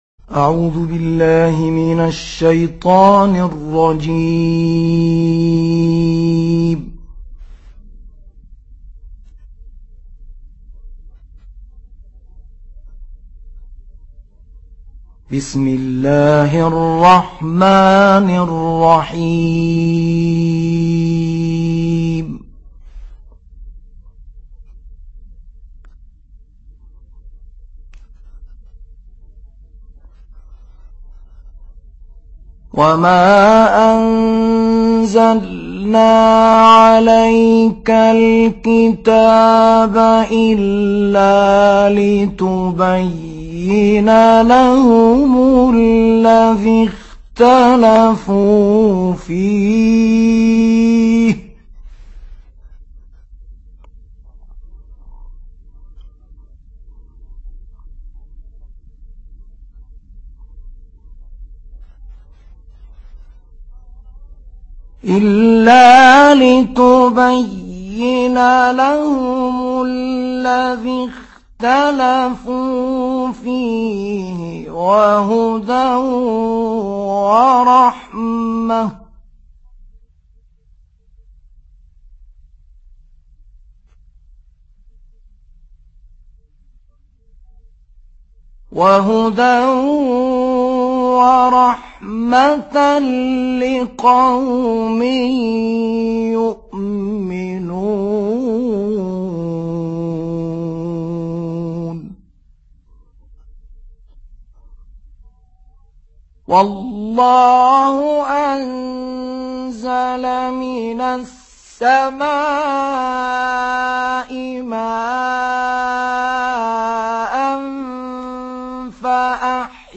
تلاوت سوره نحل